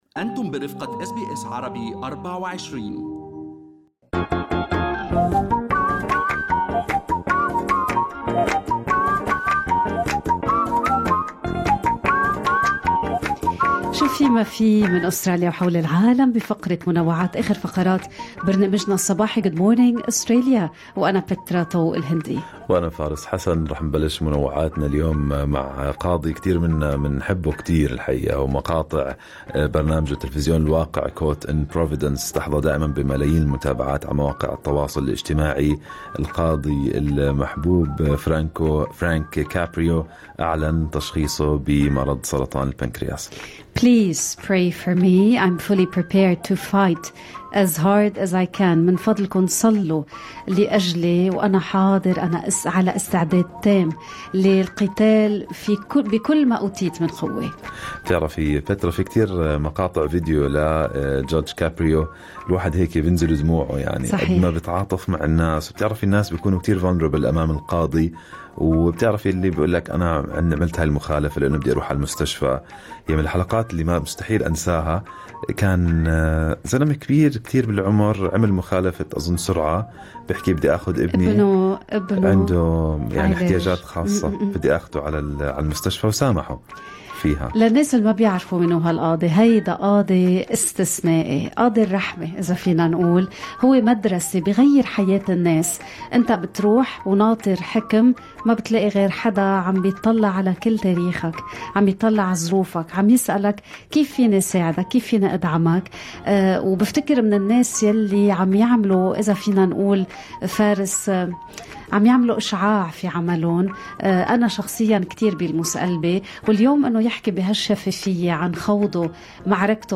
نقدم لكم فقرة المنوعات من برنامج Good Morning Australia التي تحمل إليكم بعض الأخبار والمواضيع الأكثر رواجا على مواقع التواصل الإجتماعي.